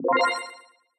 Spacey 1up/Power up
point power up sound effect free sound royalty free Memes